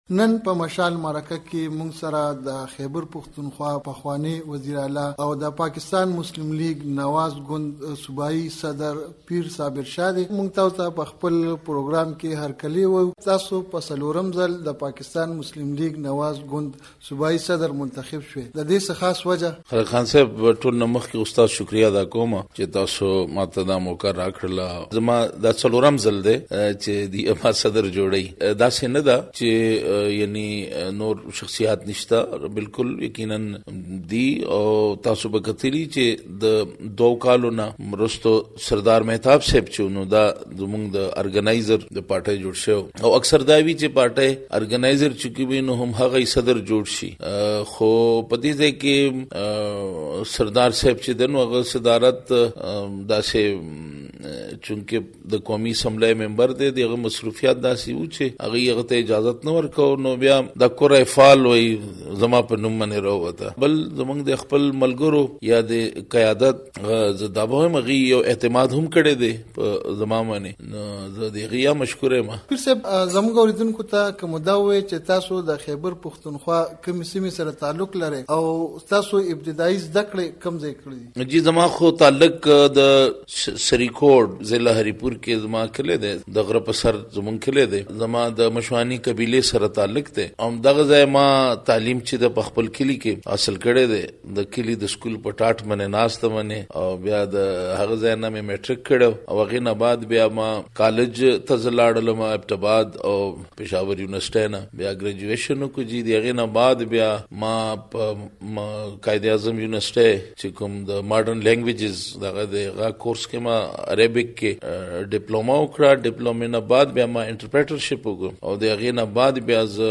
مركه